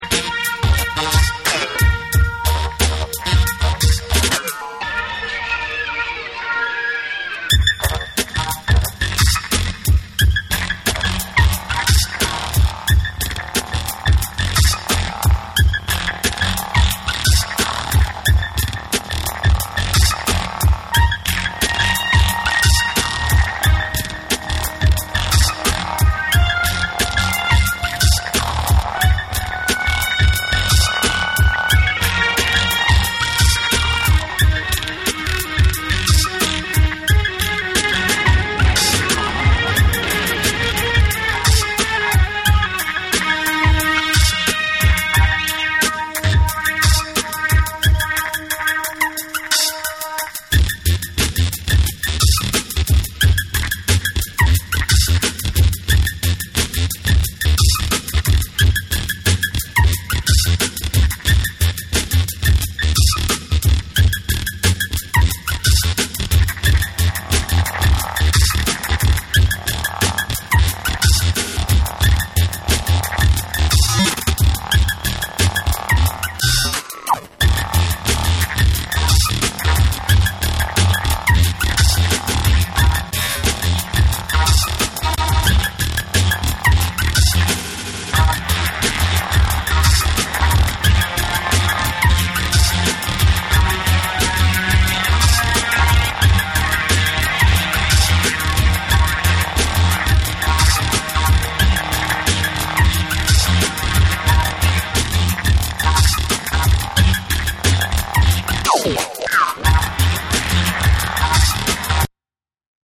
荒削りで変則的に刻まれるインパクト大なブレイクビーツにカオティックなシンセが絡む1。
BREAKBEATS